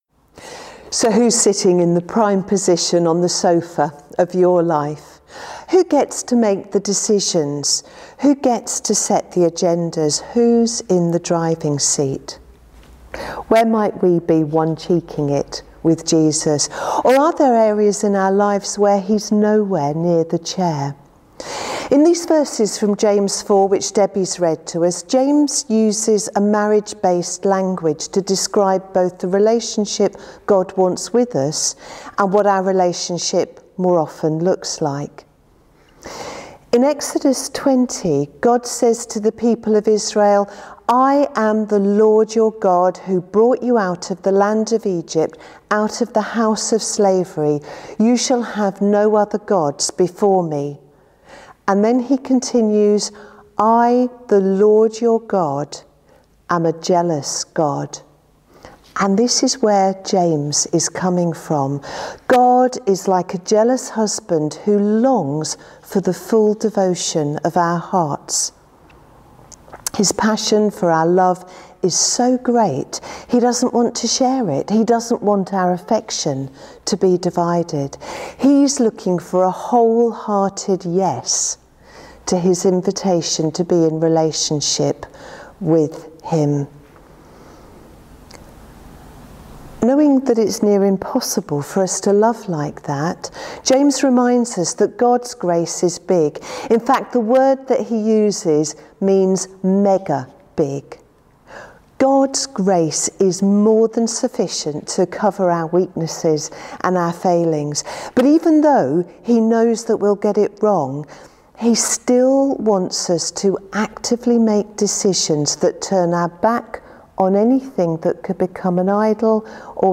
8th November sermon.mp3